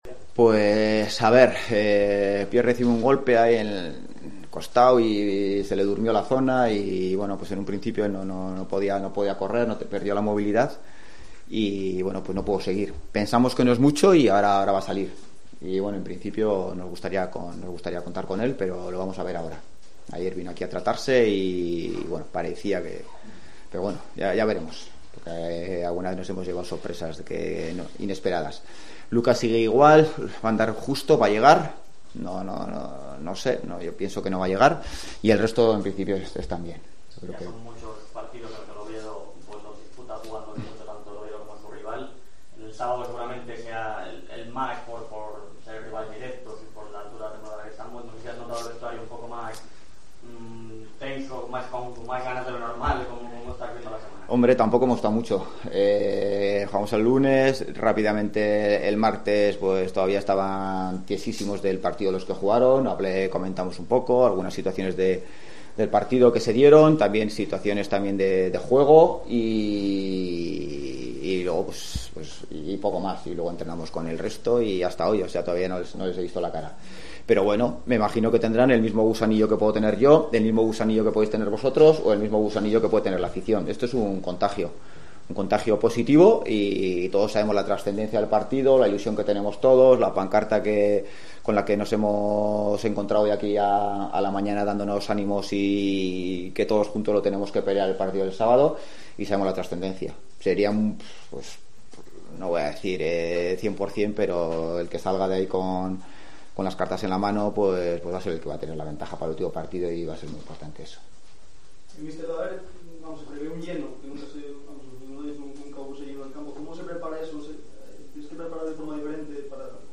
Rueda de prensa Ziganda (previa Las Palmas)